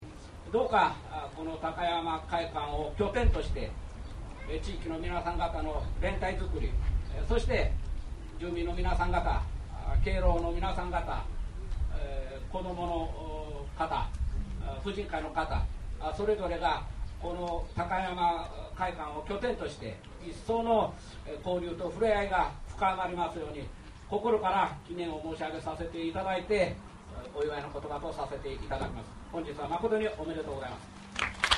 町長の祝辞があり、その後鏡割りの後、振舞いが行われた。
takayamajyukuji.mp3